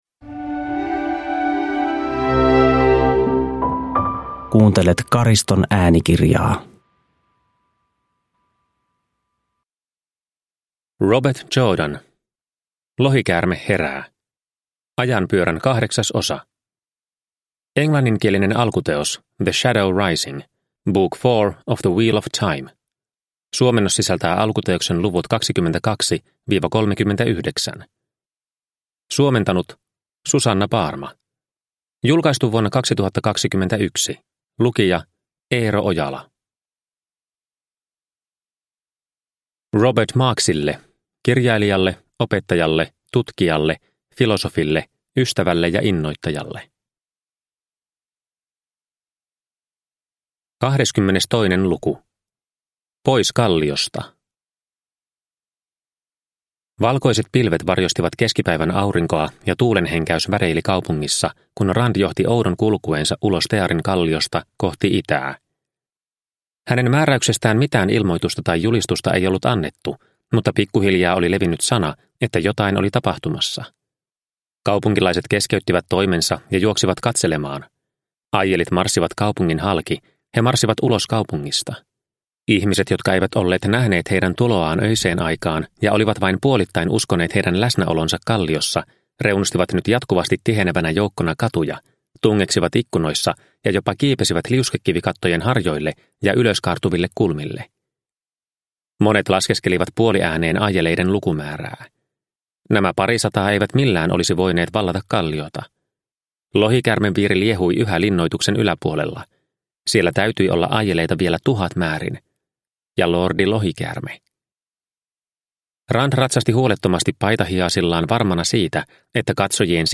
Lohikäärme herää – Ljudbok – Laddas ner